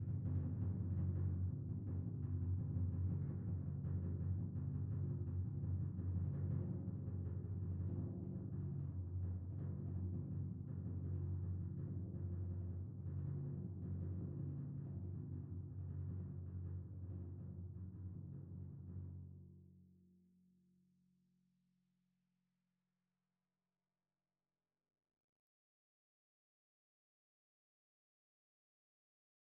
Percussion / Timpani / Rolls
Timpani1_Roll_v3_rr1_Sum.wav